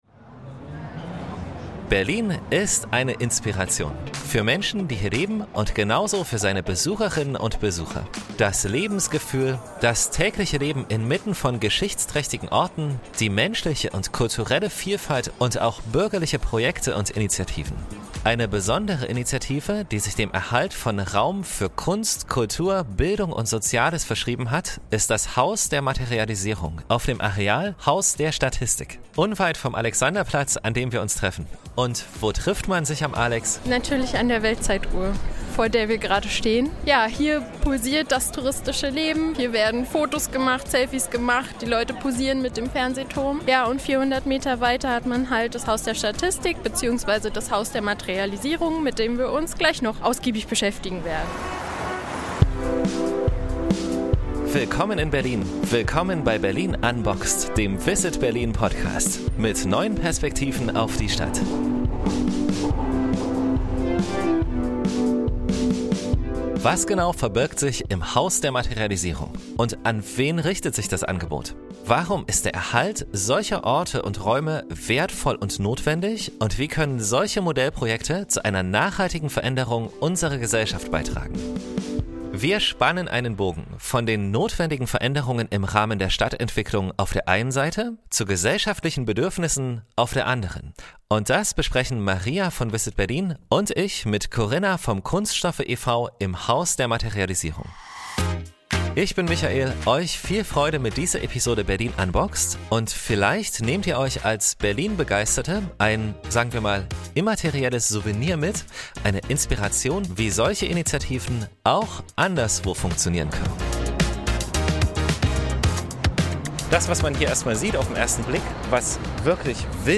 Hier erproben die Pioniere und Pionierinnen des Projekts Modelle für eine zukunftsfähige, zirkuläre Ressourcennutzung. Im Gespräch